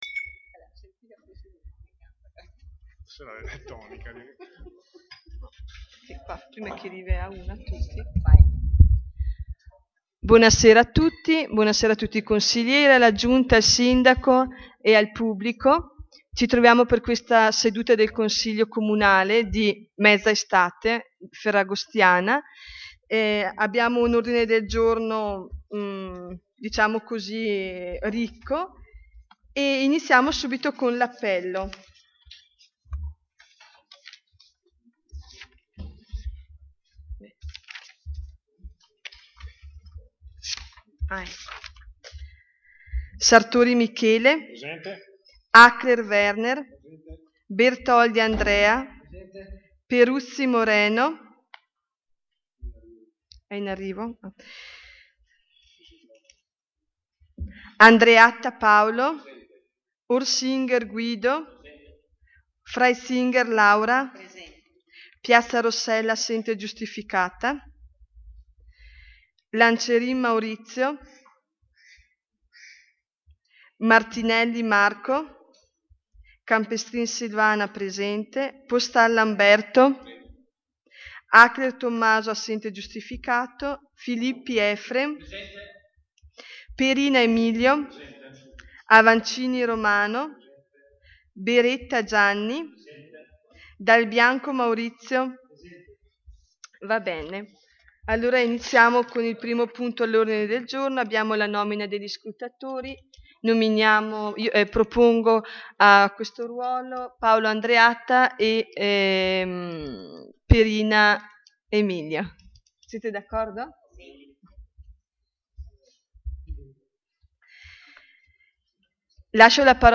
Seduta - data Giovedì, 06 Agosto 2015 / Sedute del Consiglio / Attività del consiglio / Il Consiglio Comunale / Organi politici / In Comune / Comune di Levico Terme - Comune di Levico Terme